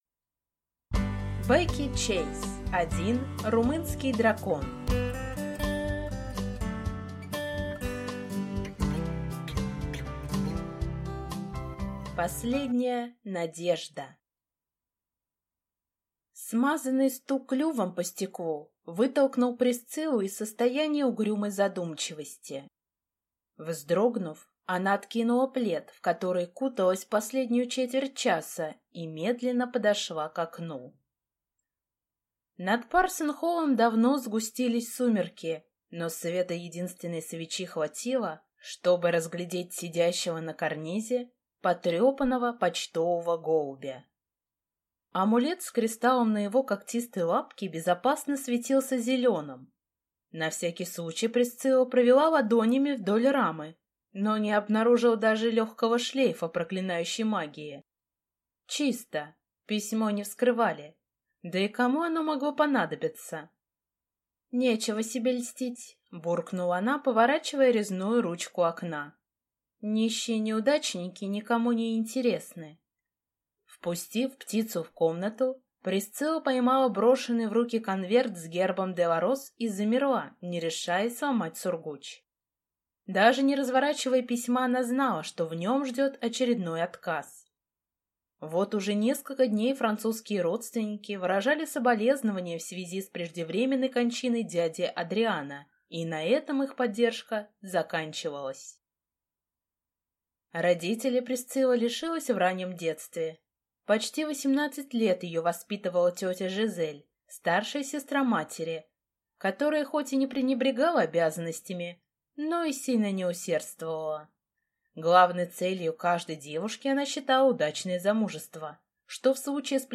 Аудиокнига Один румынский дракон | Библиотека аудиокниг